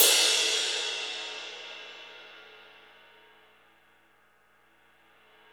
CYM XRIDE 5J.wav